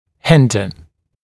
[‘hɪndə][‘хиндэ]затруднять, препятствовать, мешать